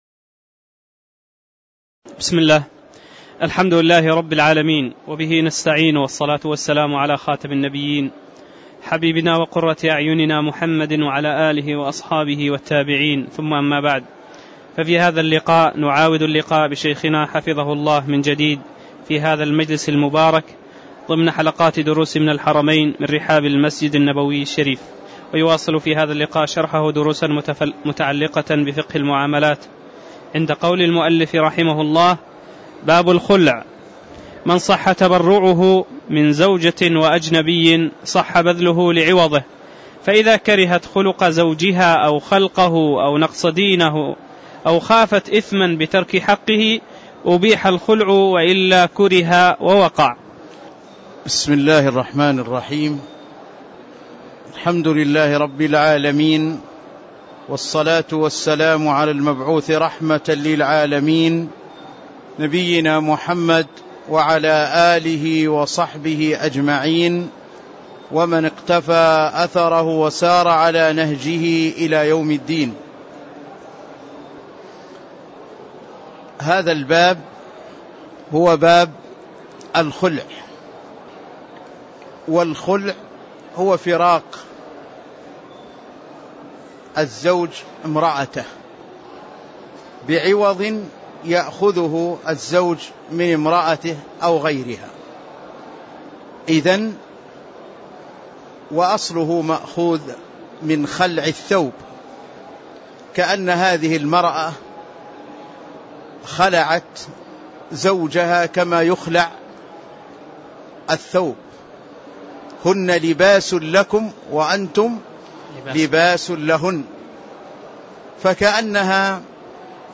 تاريخ النشر ١١ جمادى الآخرة ١٤٣٧ هـ المكان: المسجد النبوي الشيخ